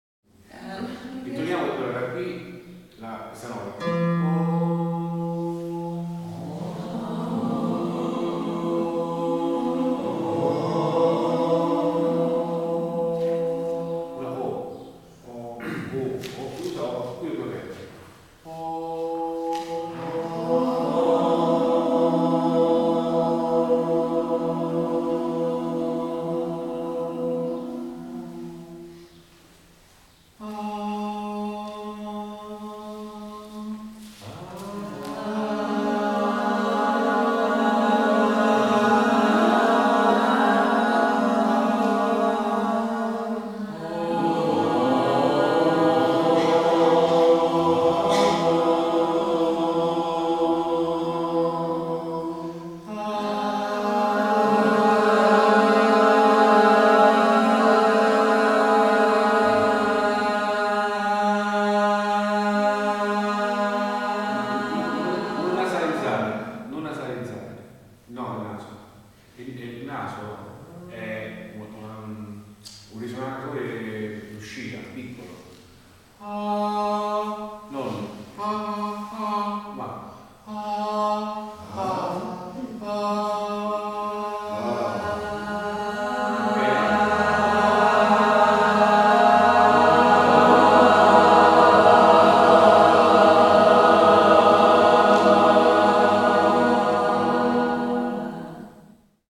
Qui potrai ascoltare alcune parti dei lavori che faremo all'interno del seminario, la durata dei brani è di circa due minuti cadauna